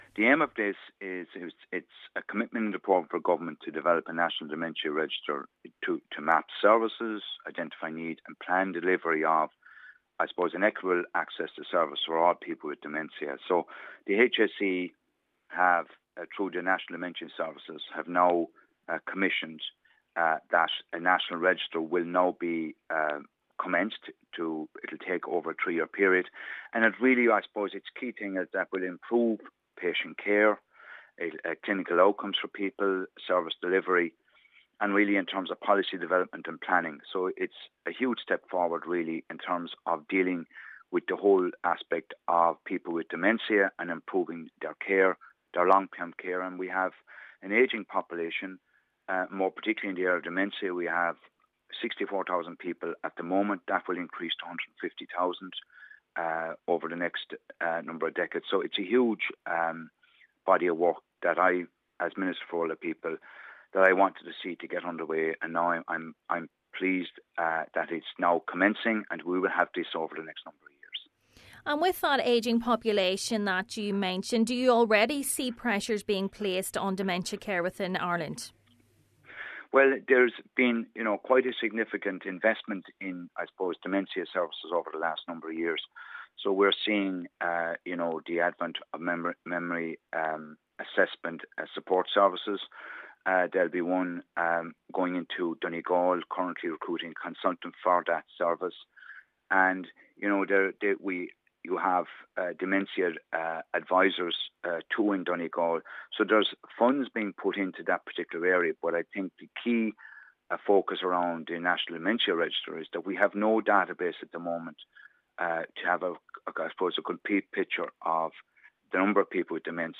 Minister O’Donnell says the register will be key to enhancing the services available for those who need care: